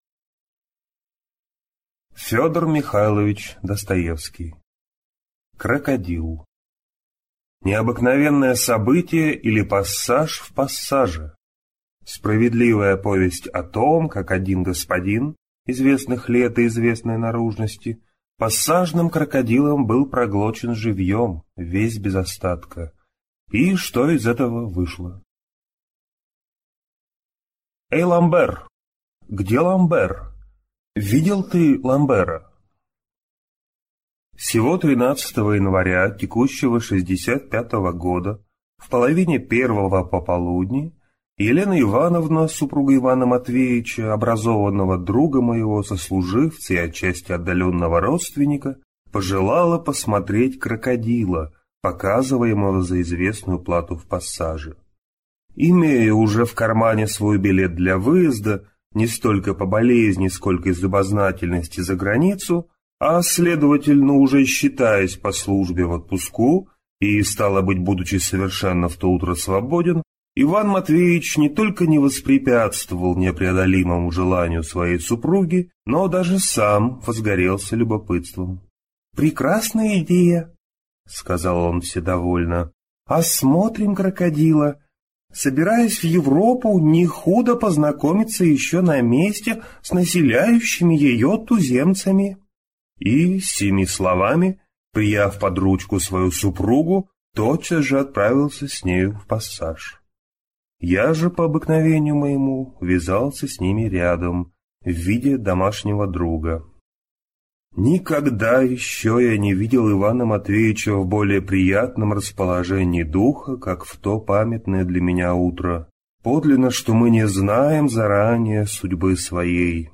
Аудиокнига Крокодил | Библиотека аудиокниг